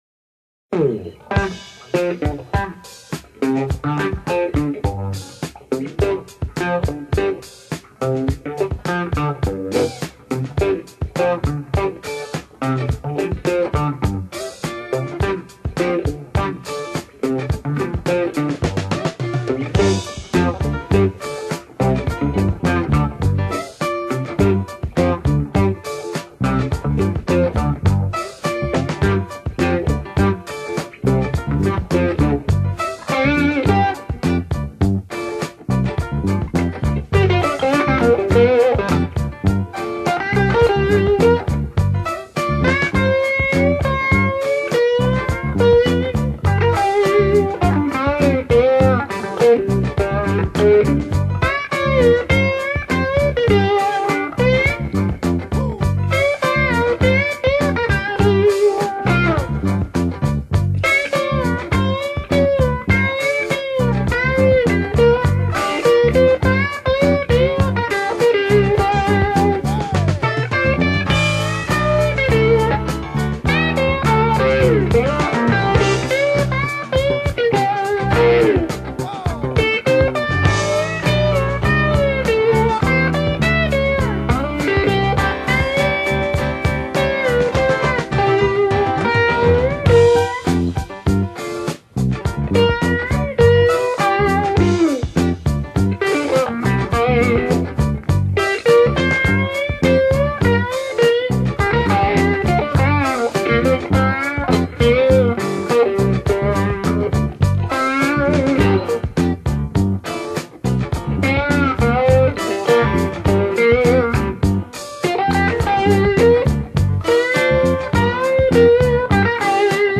音樂風格︰Blues | 1CD |